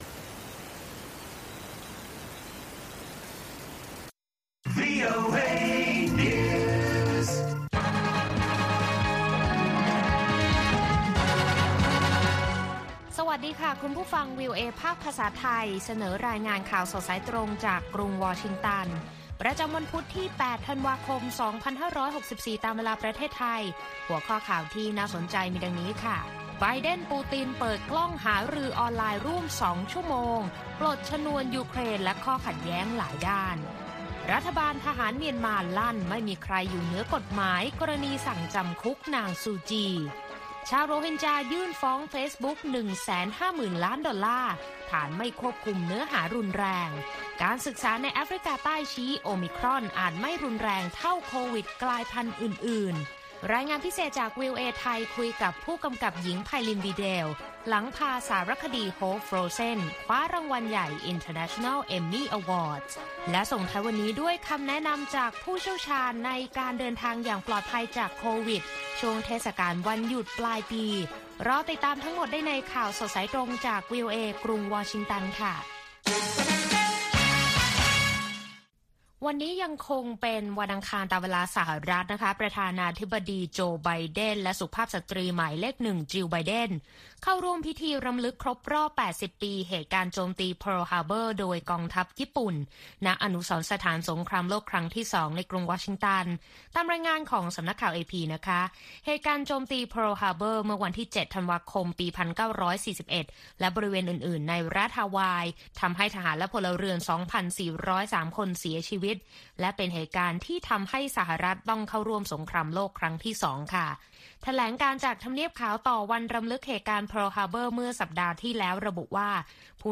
ข่าวสดสายตรงจากวีโอเอ วันพุธ ที่ 8 ธันวาคม 2564